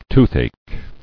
[tooth·ache]